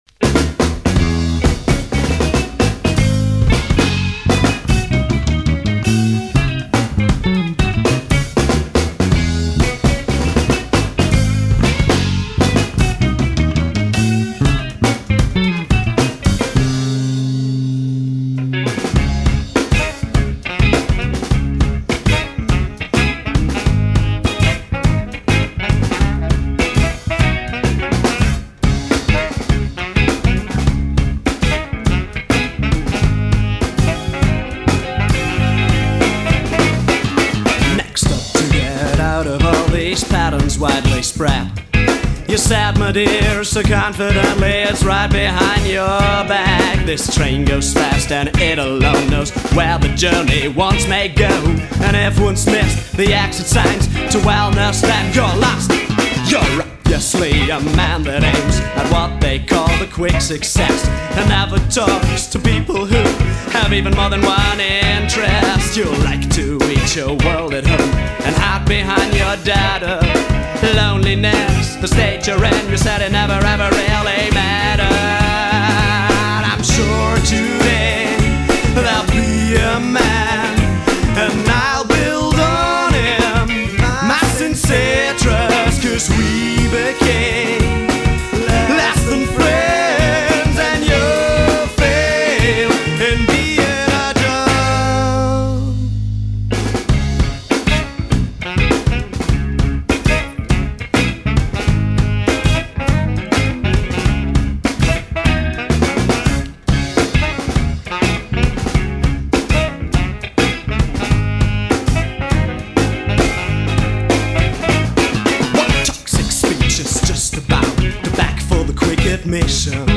im Wki-Studio in Bad Münstereifel
Trompete
Saxophon